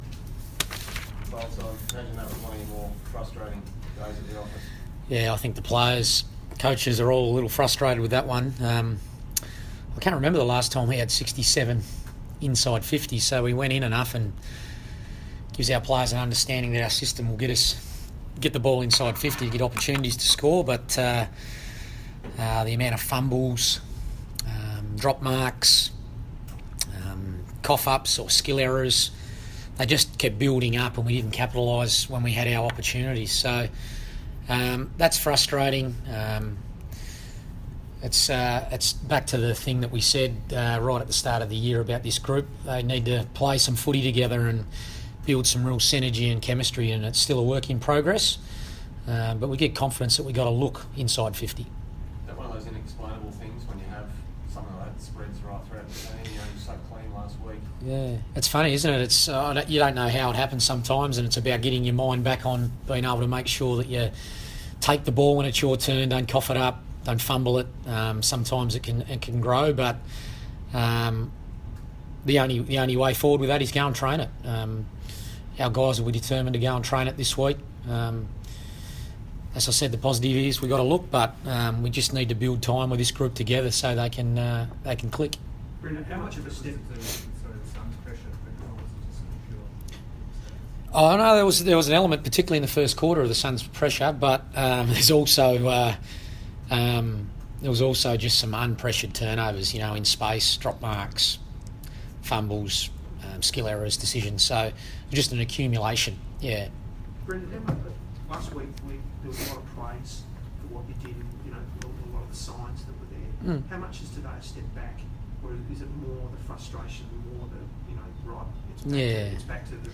Carlton coach Brendon Bolton fronts the media after the Blues' 34-point loss to Gold Coast at Etihad Stadium.